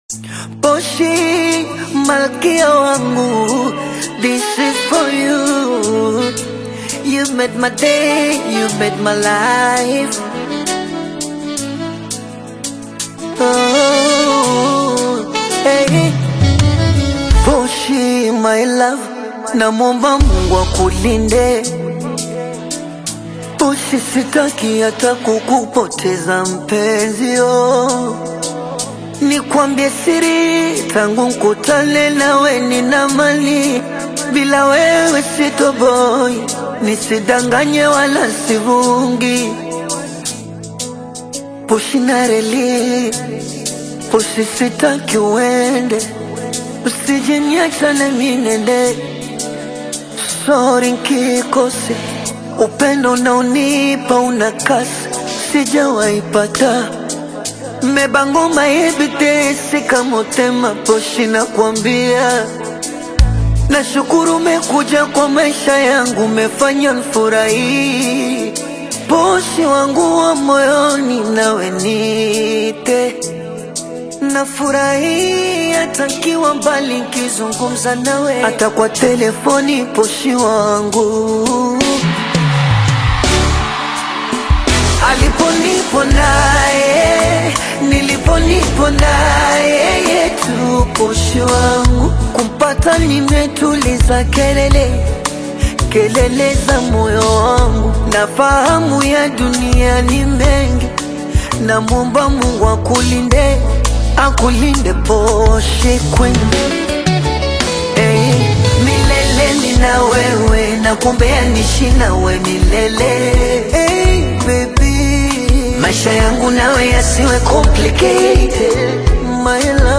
smooth Bongo Flava/Afro-Pop single
polished Afro-inspired production
expressive vocals